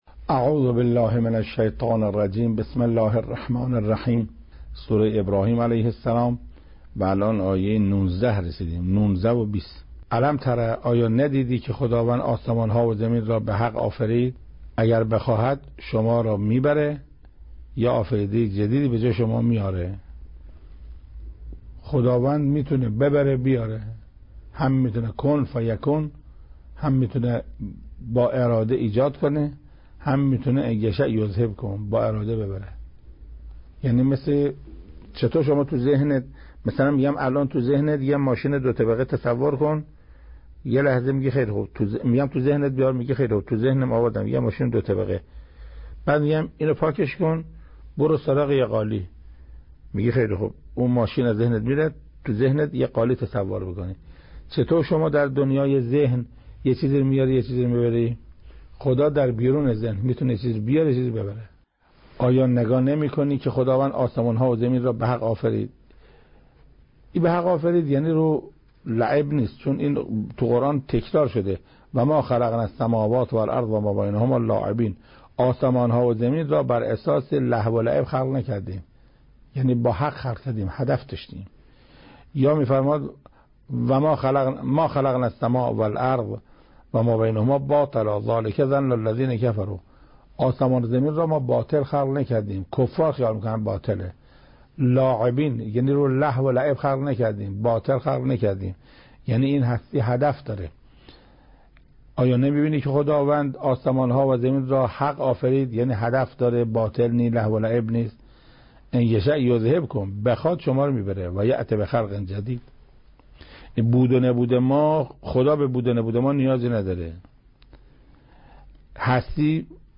ترتیل سوره(ابراهیم)